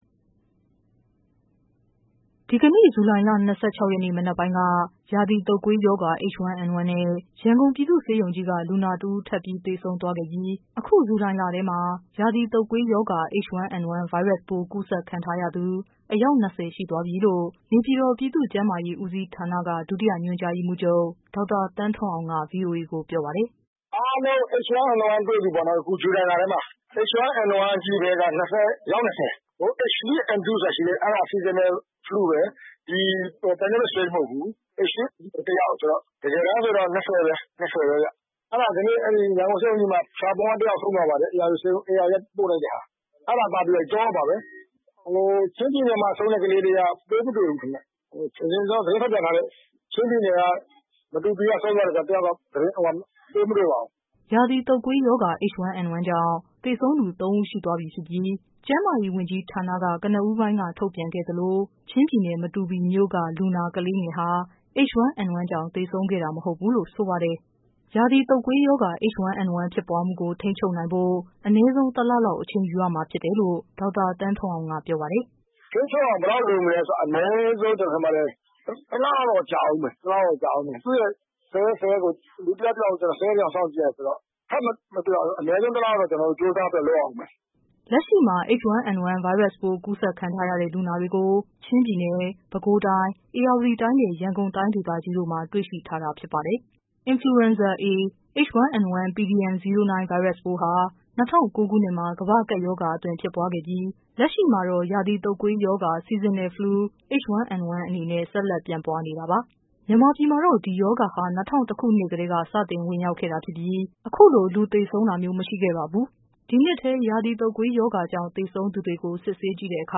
ဒီကနေ့ ဇူလိုင်လ ၂၆ရက်နေ့ မနက်ပိုင်းက ရာသီတုပ်ကွေးရောဂါ H1N1 နဲ့ ရန်ကုန် ပြည်သူ့ဆေးရုံကြီး က လူနာတဦး ထပ်သေဆုံးသွားခဲ့ပြီး အခု ဇူလိုင်လထဲမှာ ရာသီတုပ်ကွေးရောဂါ H1N1 ဗိုင်းရပ်စ်ပိုး ကူး စက်ခံရသူ အယောက် ၂၀ရှိသွားပြီလို့ နေပြည်တော် ပြည်သူ့ကျန်းမာရေးဦးစီးဌာနက ဒုတိယ ညွှန်ကြား ရေးမှူးချုပ် ဒေါက်တာ သန်းထွန်းအောင်က ဗွီအိုအေကို ပြောပါတယ်။